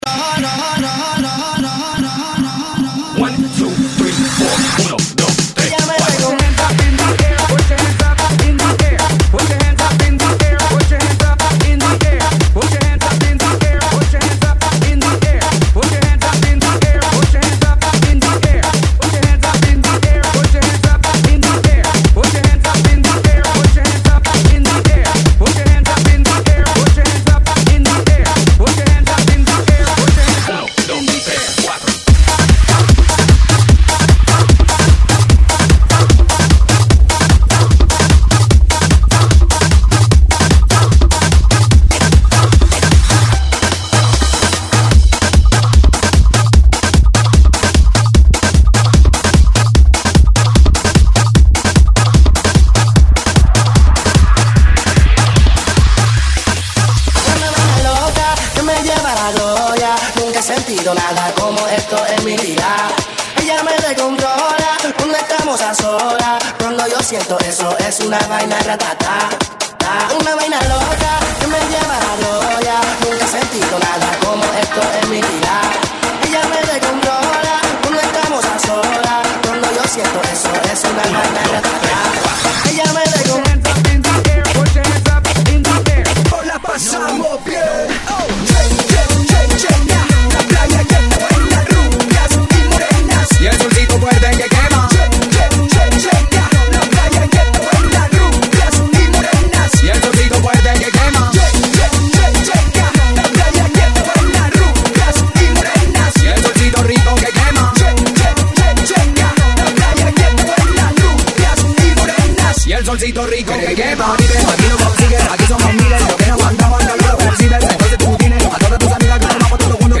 GENERO: LATINO